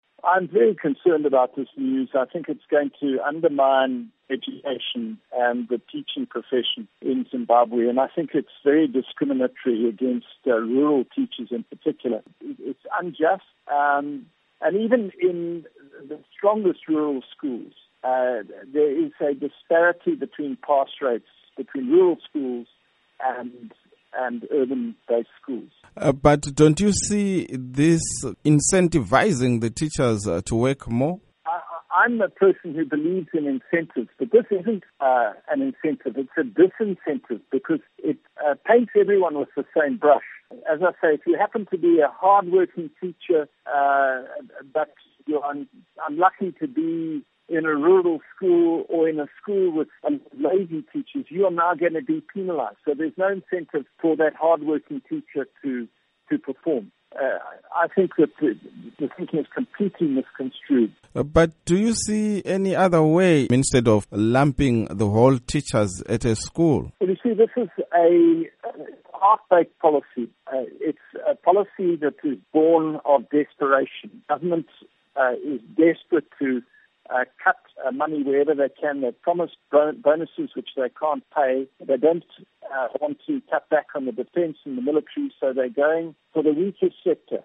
Interview With David Coltart